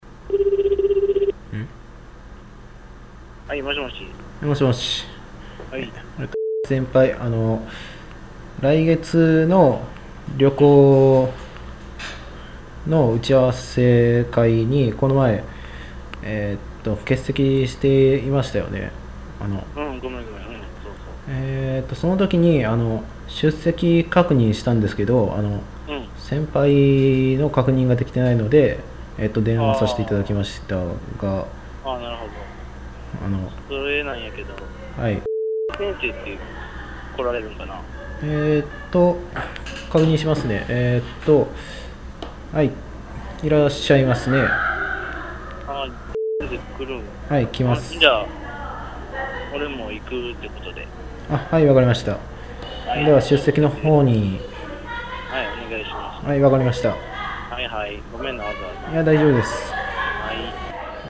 方言ロールプレイ会話データベース リーグ戦式ロールプレイ会話＜相生＞
相生若年層男性グループ２